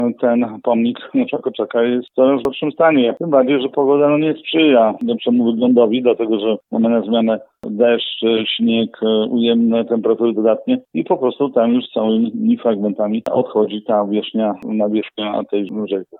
Podobnego zdania jest Jacek Budziński, radny miasta, który wielokrotnie składał interpelacje w tej sprawie: